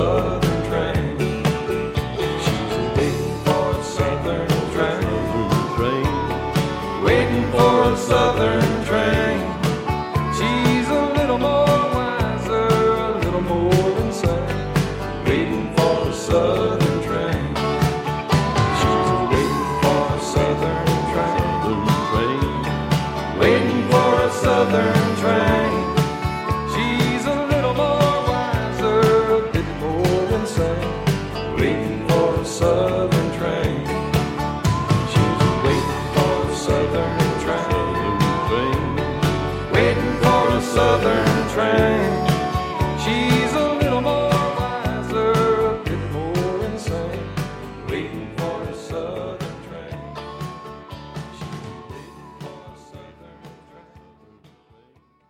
sa voix grave et merveilleuse